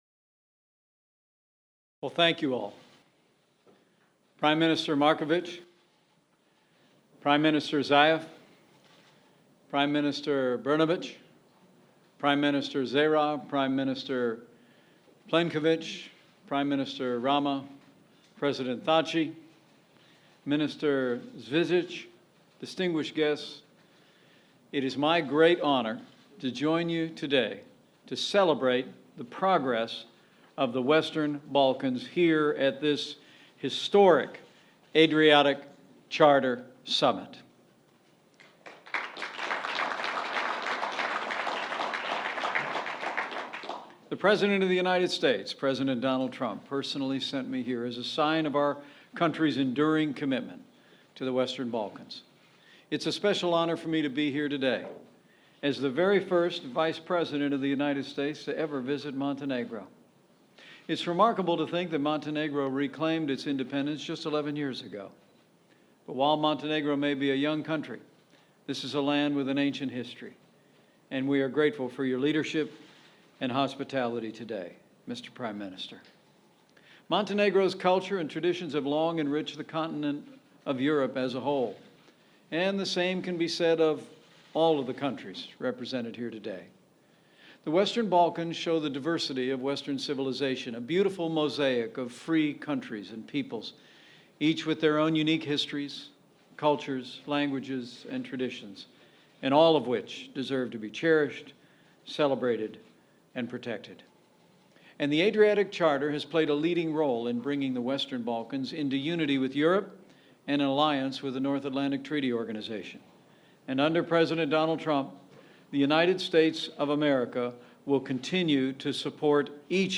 U.S. Vice President Mike Pence speaks at the Adriatic Charter Summit
Vice President Mike Pence talks about the United States' continued commitment to supporting the Western Balkans. Pence asserts that under President Trump, the U.S. will continue to support an independent Balkans and that the U.S. will hold Russia accountable for its actions and will continue to support the current sanctions against Russia. Pence speaks at the Adriatic Charter Summit held at the Hilton Podgorica Crna Gora hotel in Podgorica, Montenegro.